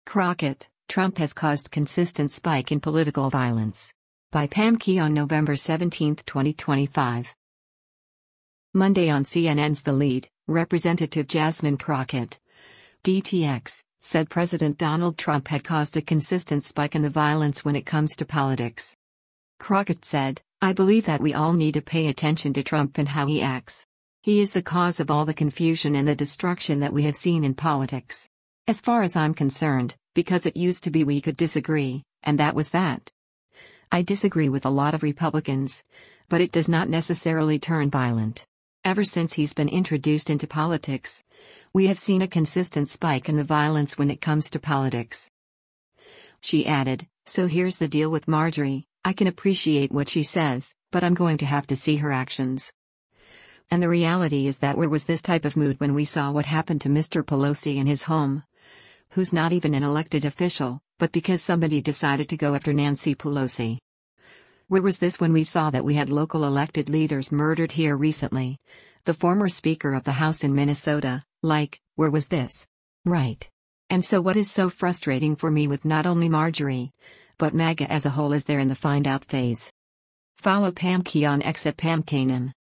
Monday on CNN’s “The Lead,” Rep. Jasmine Crockett (D-TX) said President Donald Trump had caused a “consistent spike in the violence when it comes to politics.”